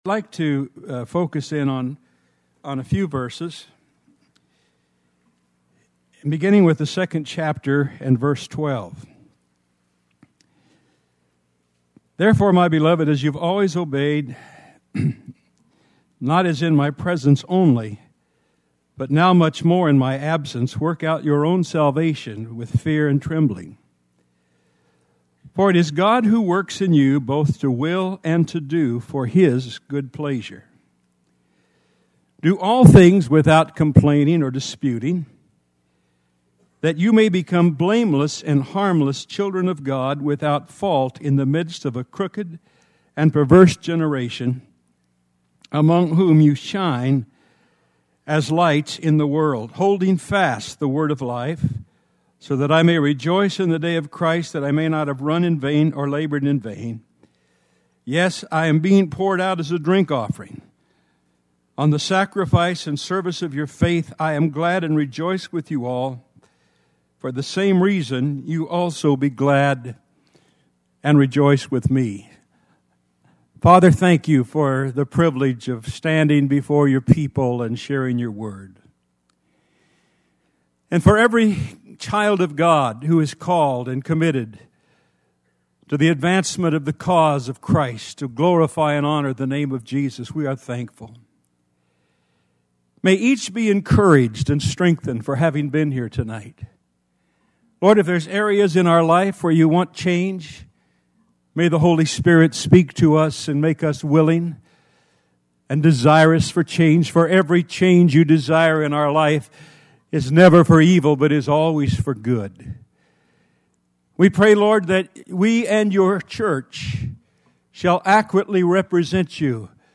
2005 DSPC Conference: Pastors & Leaders Date